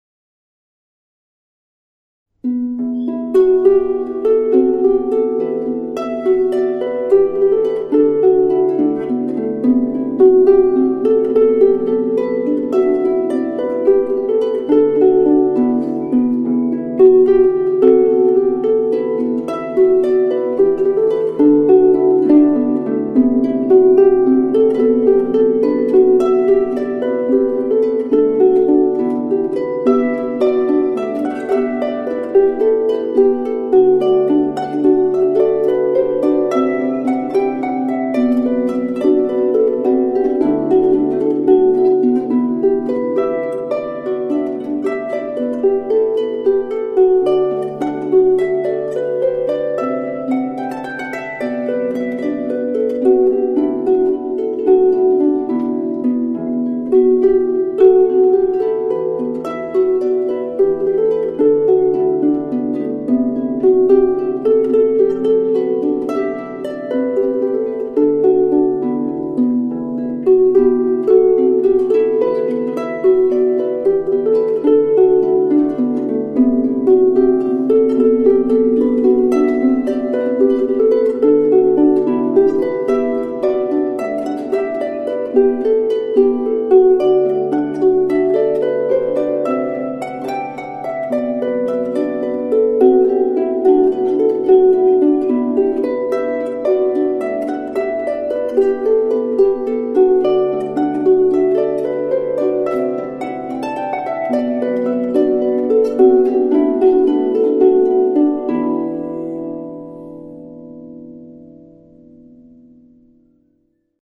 harp air solo air op de keltische harp
live-opname 2004 concert Poitín, Ruïnekerk, Bergen NH